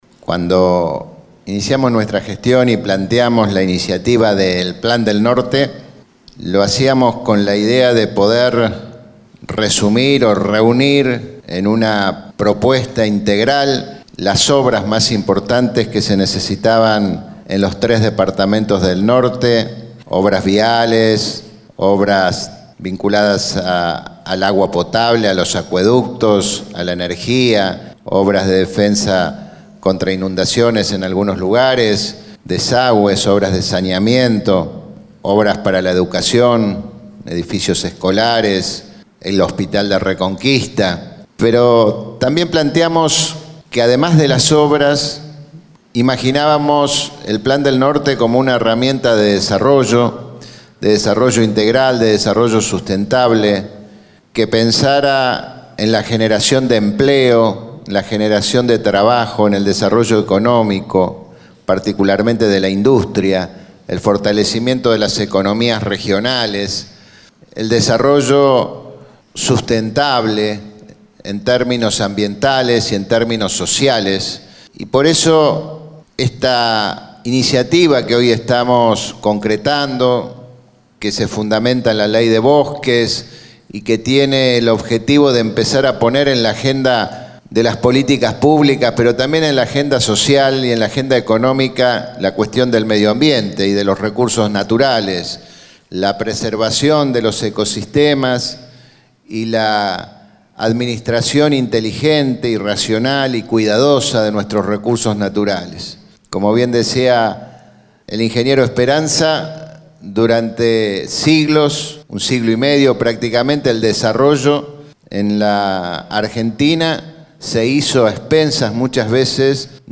El acto se realizó en la Casa del Bicentenario, bulevar Belgrano 787, de la localidad de Reconquista.